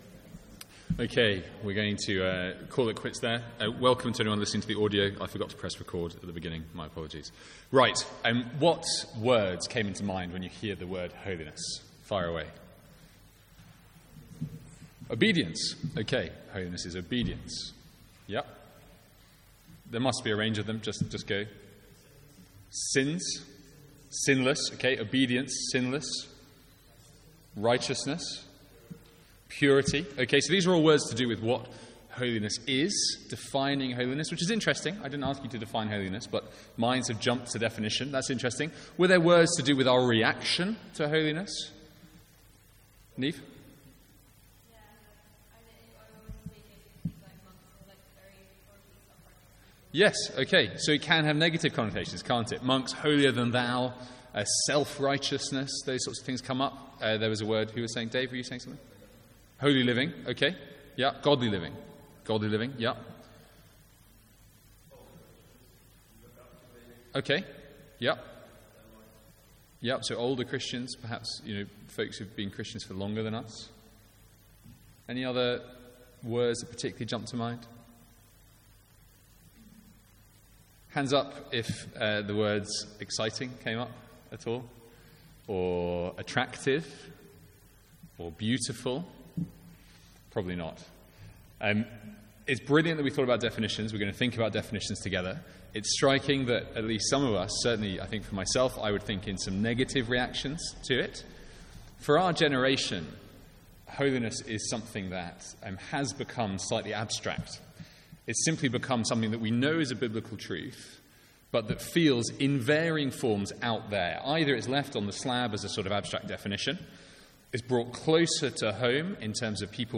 Seminar from MYC16: Identity.